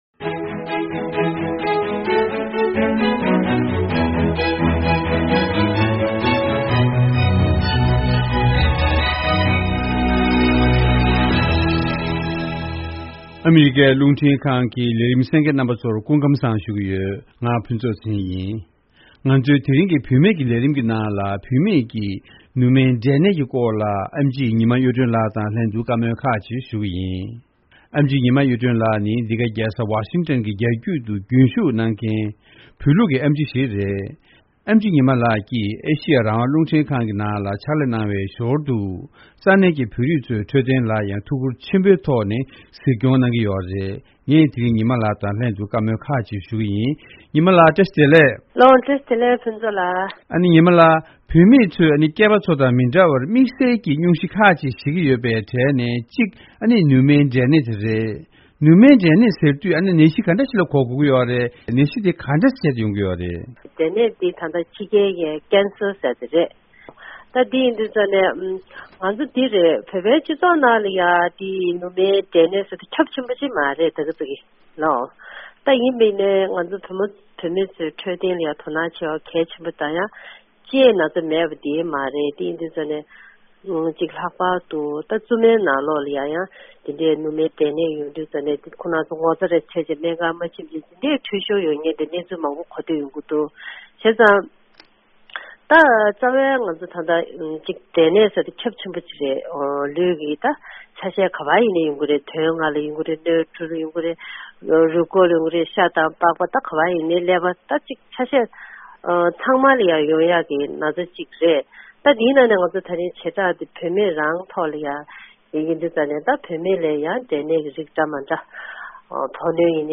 བཅར་འདྲི་ཞུས་པའི་བུད་མེད་ཀྱི་ལེ་ཚན་དེ་གསན་རོགས་གནང་།